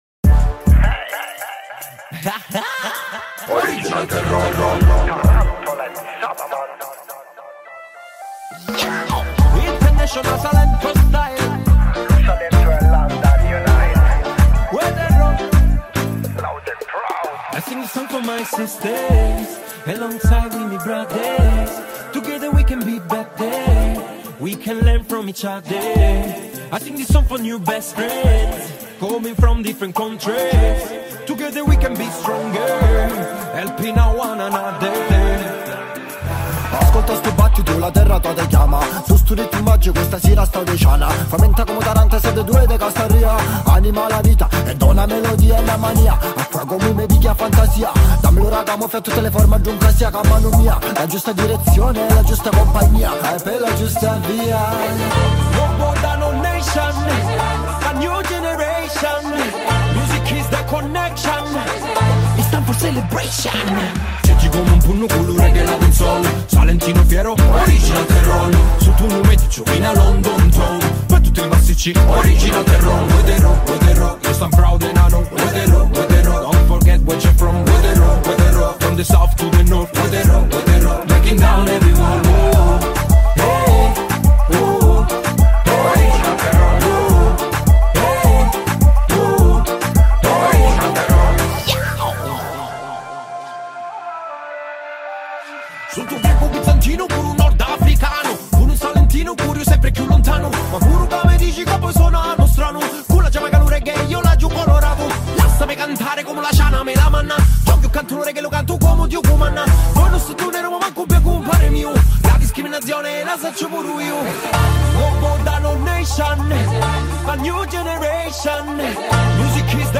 💬 Contenuti dell’intervista
🎧 Un podcast che vibra tra parole e ritmi, alla scoperta di musica come resistenza e rinascita 📡